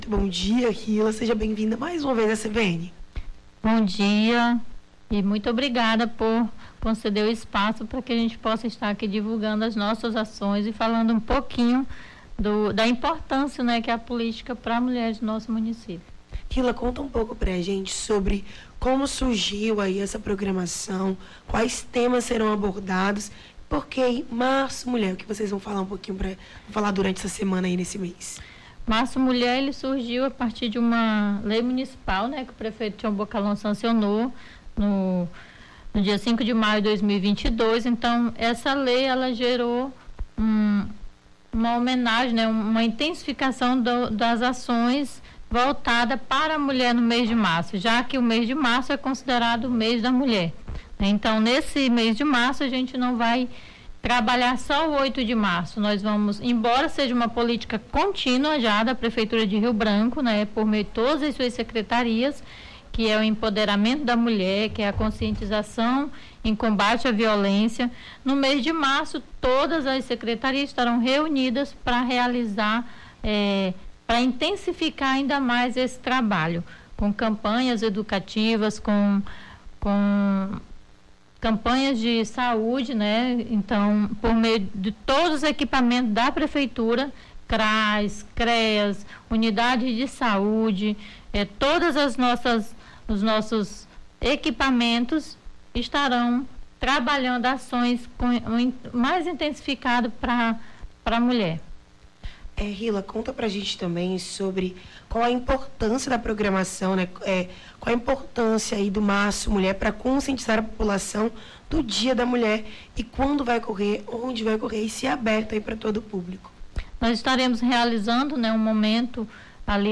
Nome do Artista - CENSURA - ENTREVISTA (LANÇAMENTO DA LEI MARÇO MULHER) 06-03-23.mp3